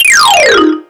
NFF-chromatic-fall (3).wav